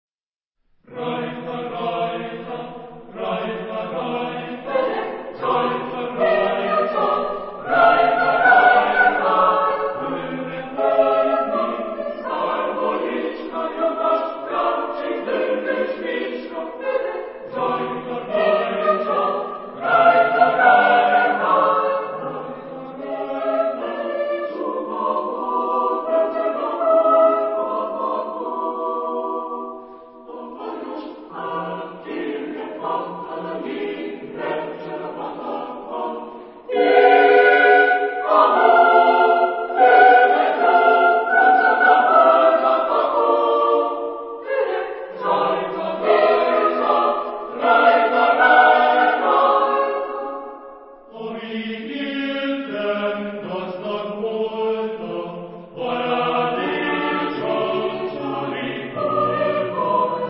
Género/Estilo/Forma: Renacimiento ; Profano
Tipo de formación coral: SATB  (4 voces Coro mixto )